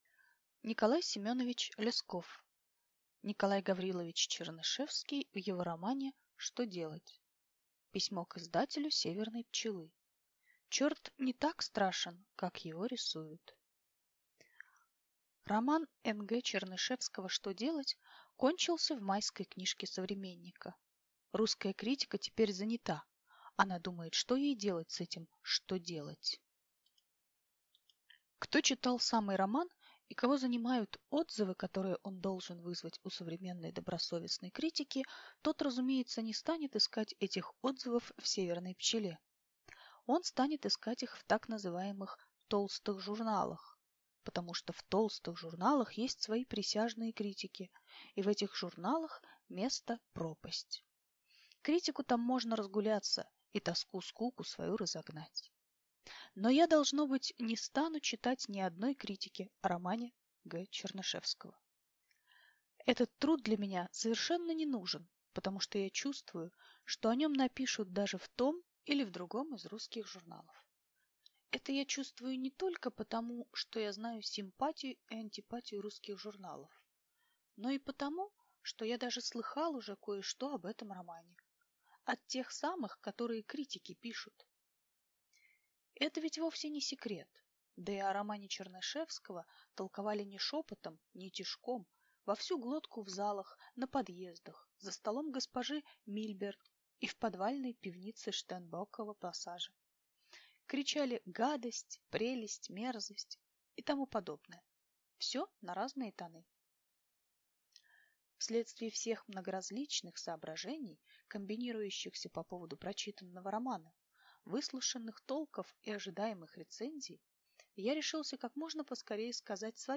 Аудиокнига Николай Гаврилович Чернышевский в его романе «Что делать?» | Библиотека аудиокниг